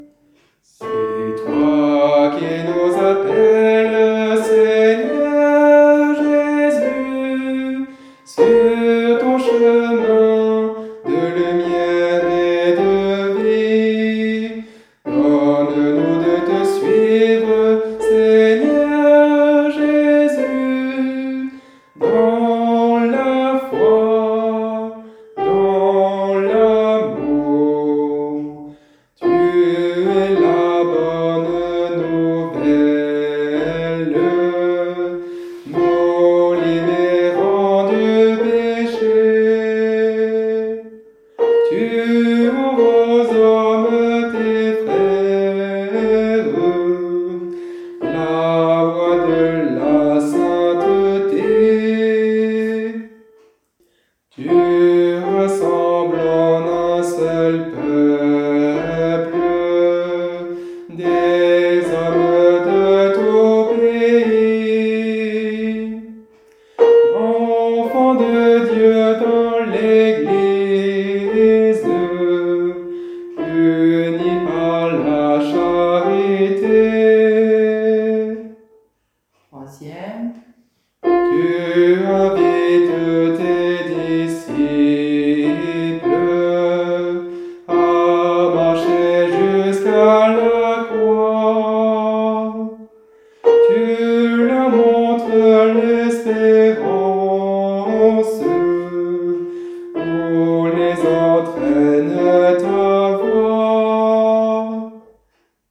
Polyphonies et voix disponibles